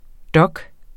Udtale [ ˈdʌg ]